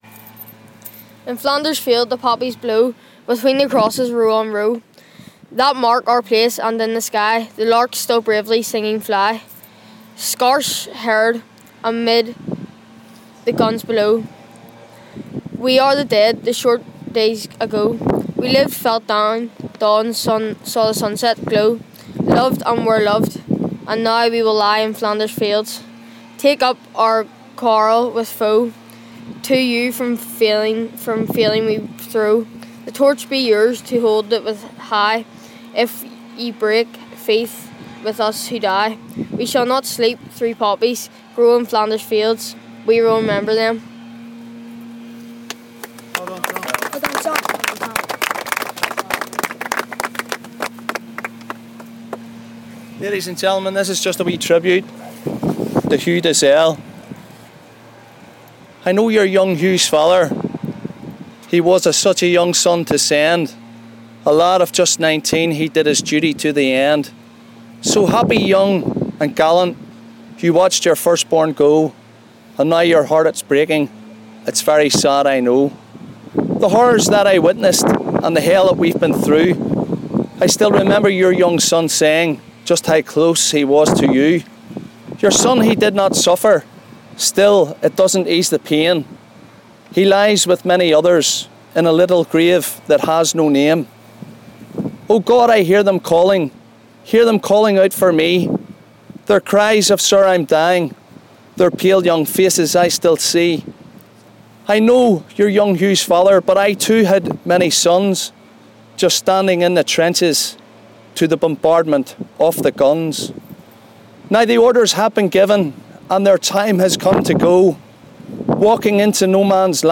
A time of reflection from Tyne Cot Cemetery